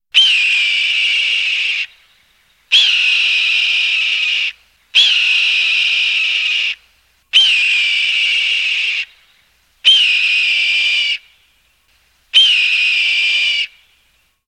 cry-of-a-hawk